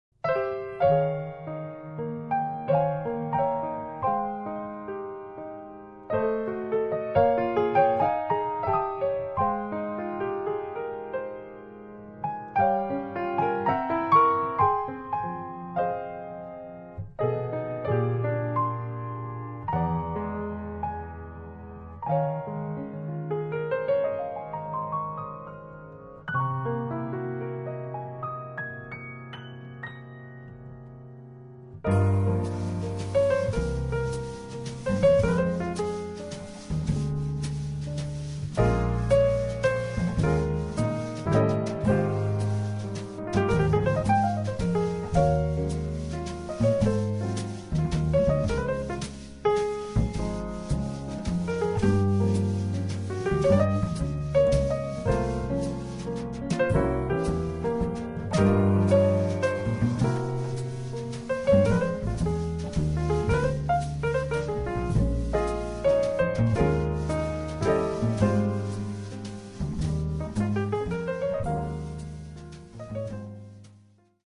piano
contrabbasso
batteria